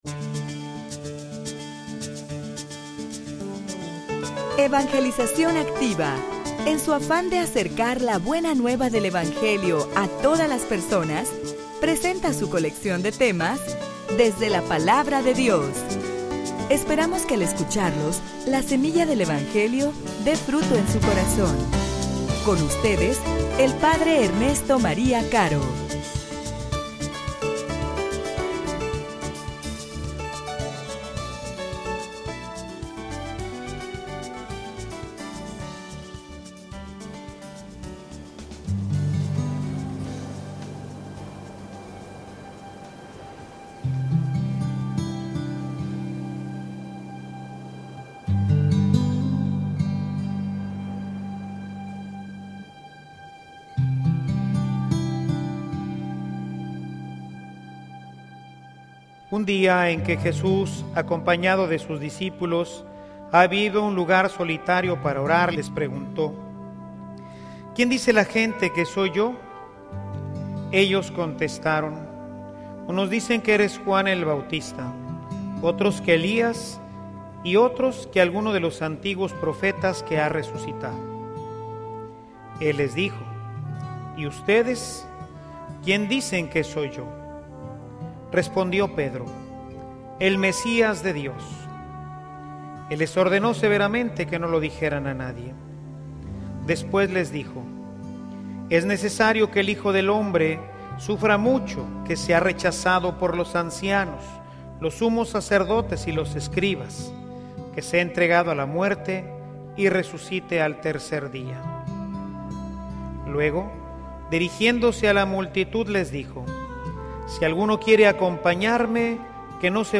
homilia_La_falsa_felicidad_del_mundo.mp3